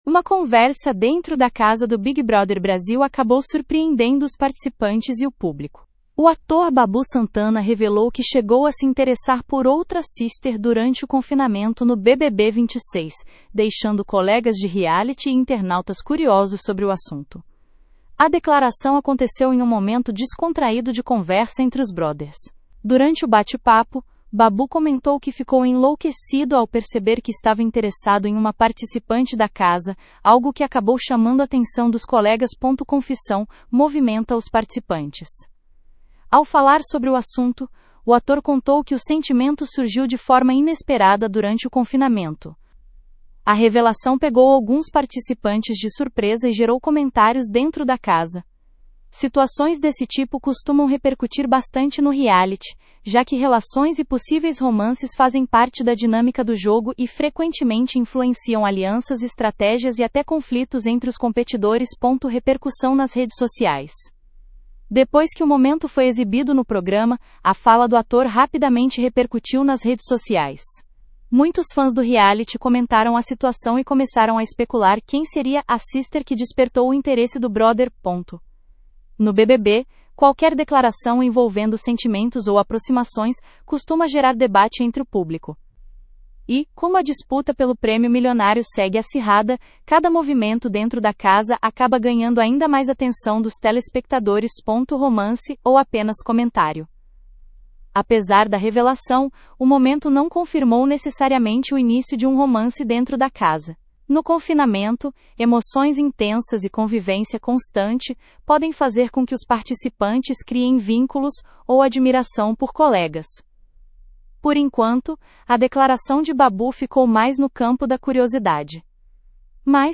A declaração aconteceu em um momento descontraído de conversa entre os brothers.